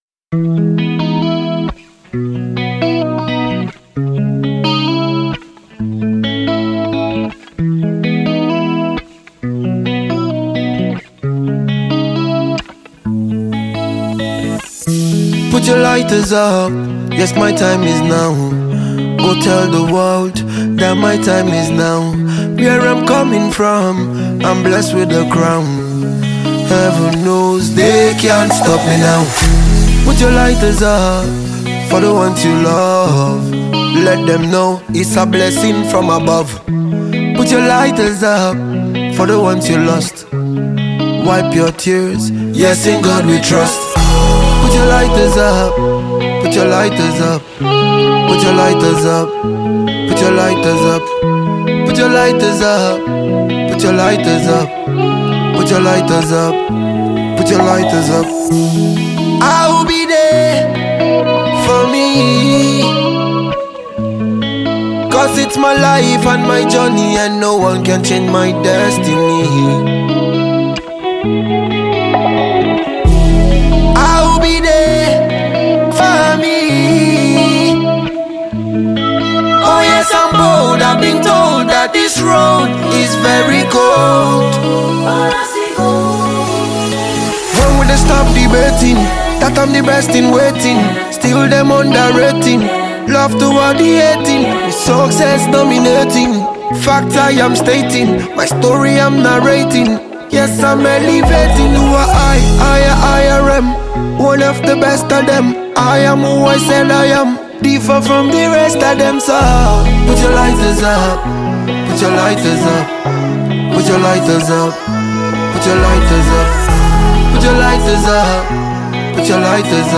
Nigerian dancehall singer and songwriter